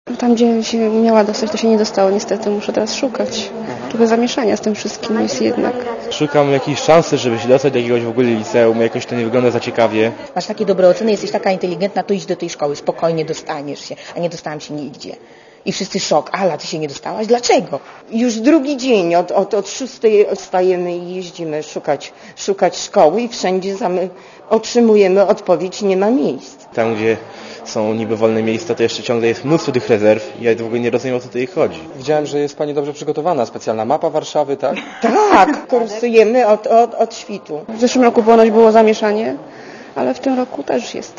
(RadioZet) Źródło: (RadioZet) Posłuchaj relacji (164 KB) Przedstawiciele kuratorium zapewniają, że będą pomagać gimnazjalistom, aż wszyscy znajdą miejsce w szkole.